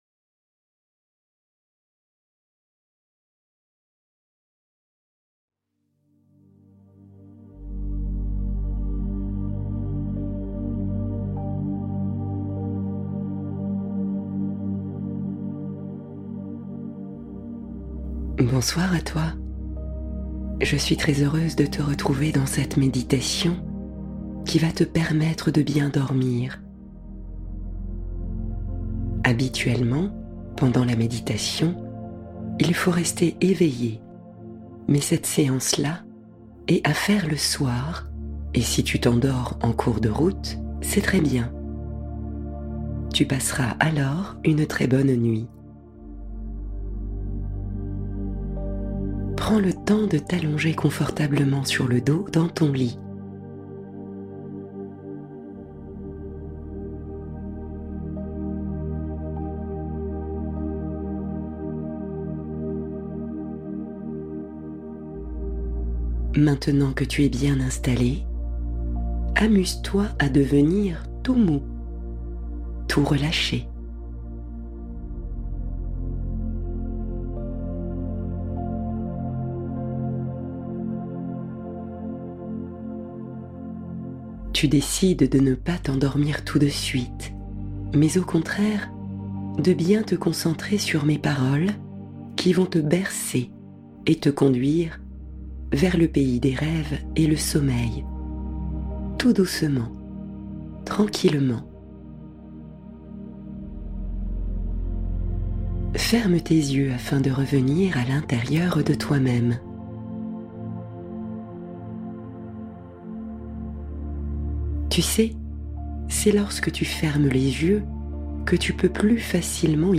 Conte du Soir : Histoire douce pour endormir petits et grands